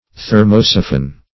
Search Result for " thermosiphon" : The Collaborative International Dictionary of English v.0.48: Thermosiphon \Ther`mo*si"phon\, n. An arrangement of siphon tubes for assisting circulation in a liquid.